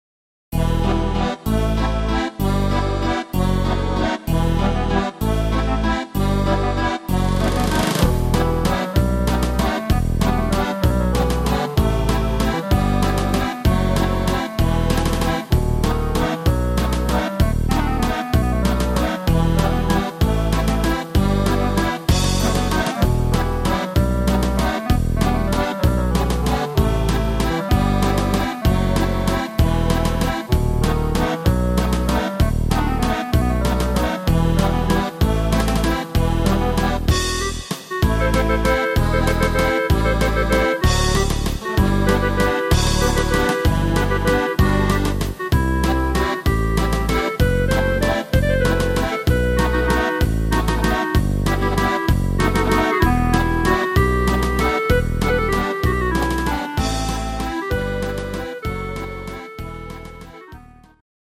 inst. Akkordeon